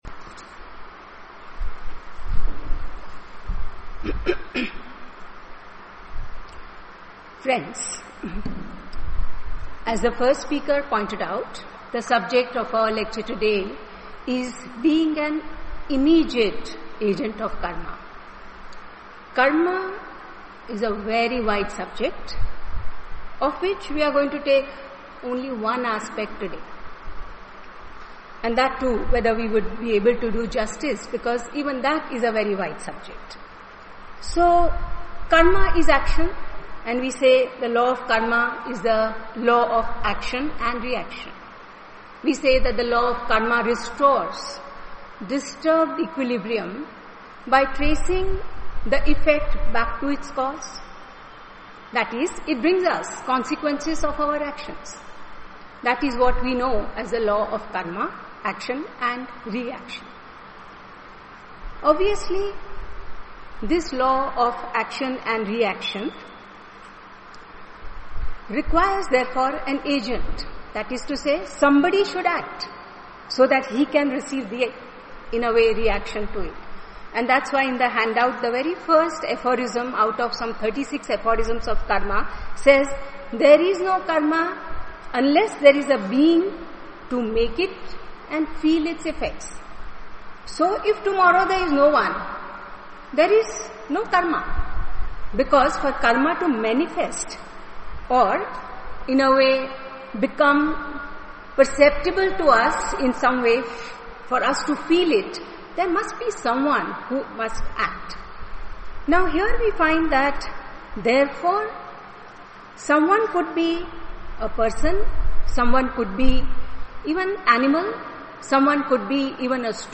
Dear Subscriber, An Audio Lecture entitled Being an Immediate Agent of Karma has been recorded and uploaded to our web site.